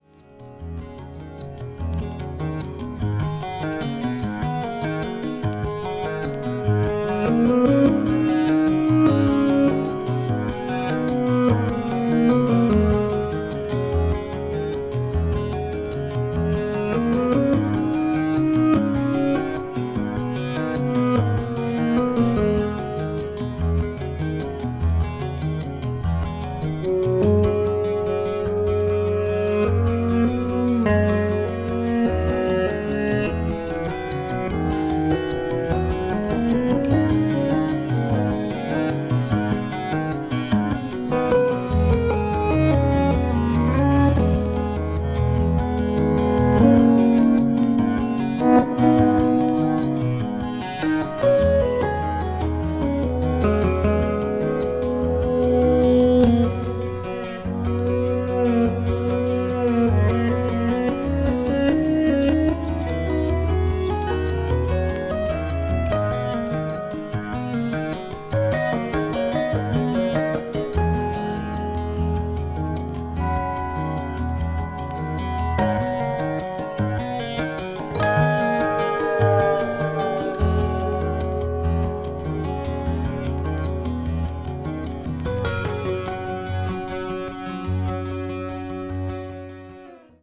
奥行きのある立体的な音響も最高です。